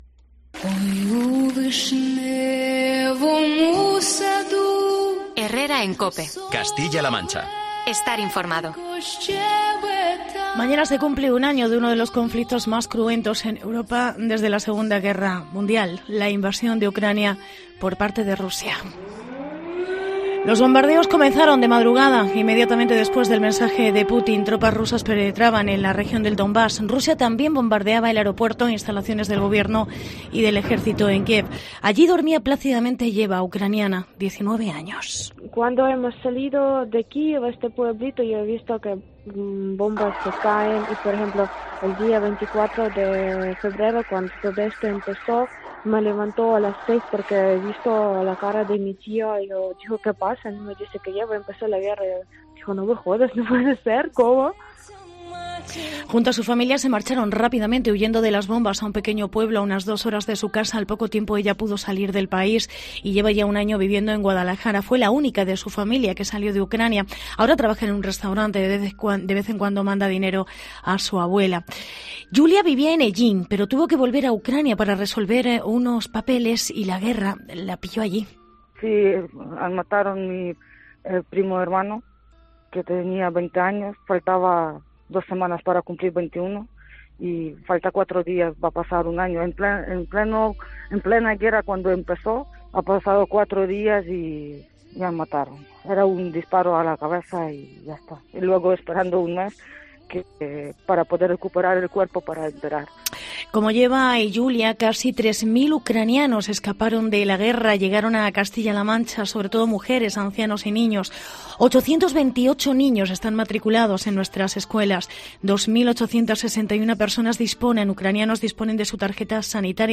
En Herrera en COPE CLM hemos hablado con ucranianos que se refugiaron en Castilla-La Mancha.